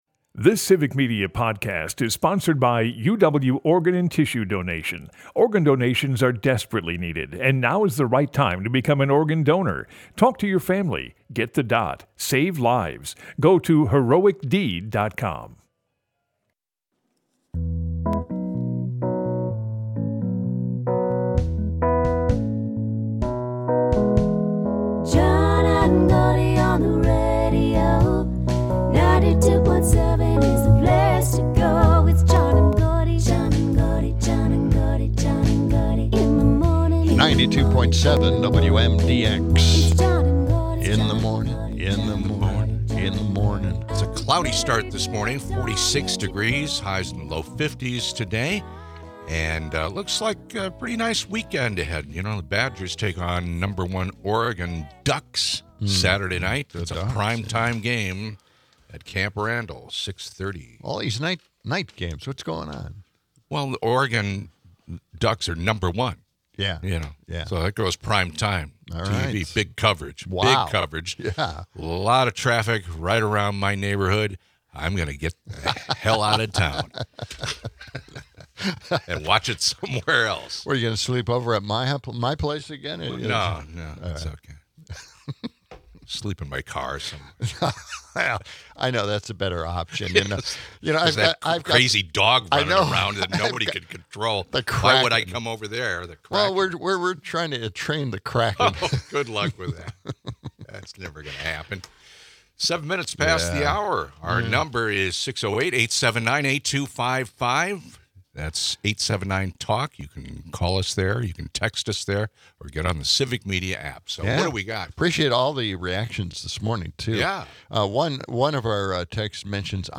Broadcasts live 6 - 8am weekdays in Madison.